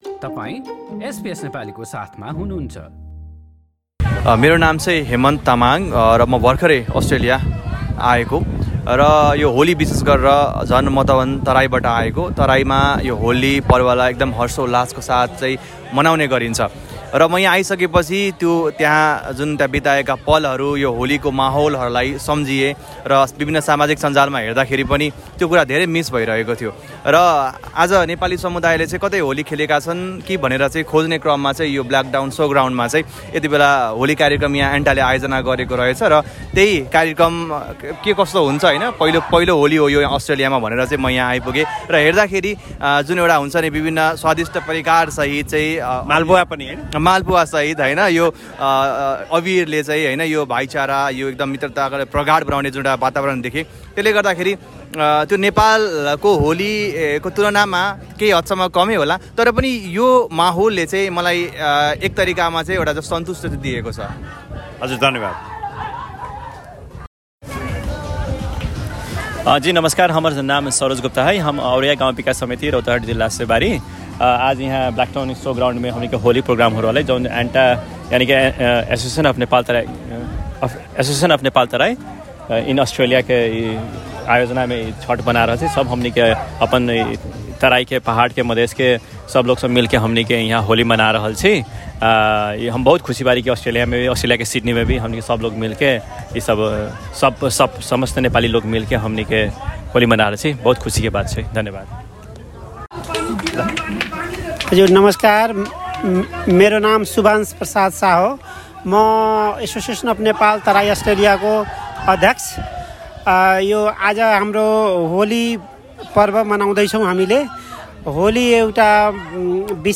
Some participants talked with SBS Nepali.